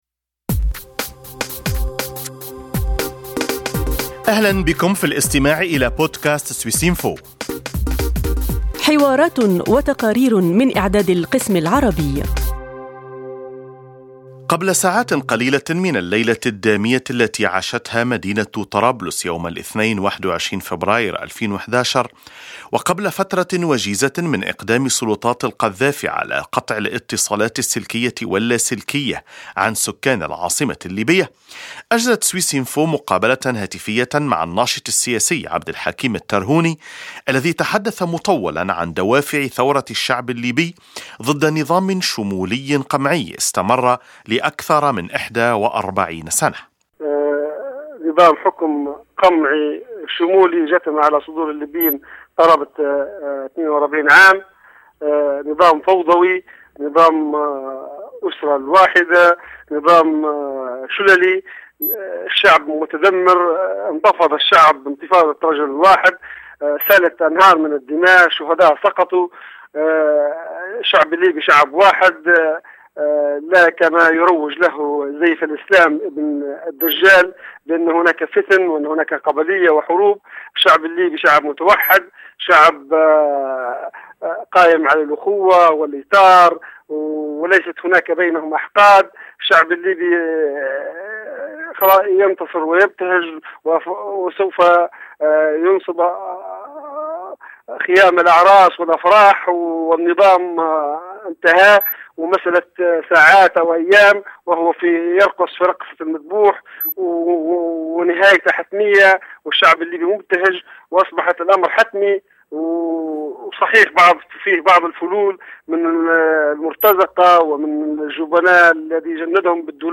يتحدث من طرابلس عن دوافع ثورة الشعب الليبي ضد نظام شمولي قمعي استمر لأكثر من إحدى وأربعين سنة.